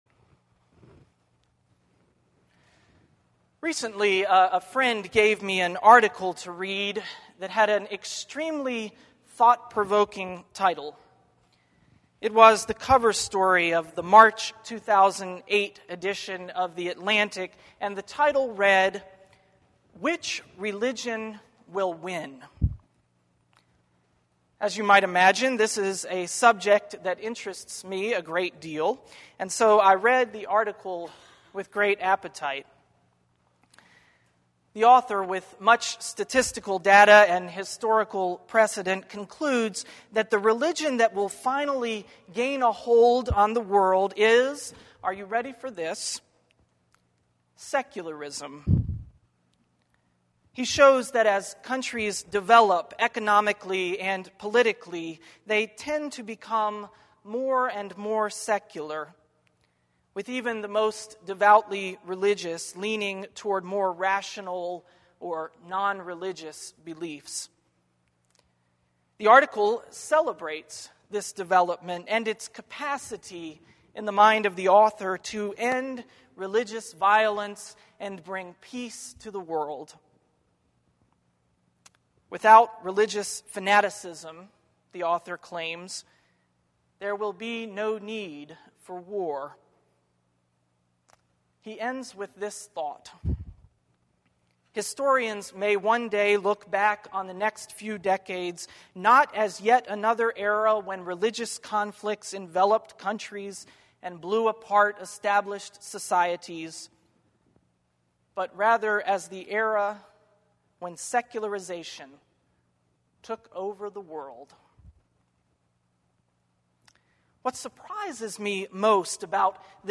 Sermon_-_The_Faith_of_Pharaoh615.mp3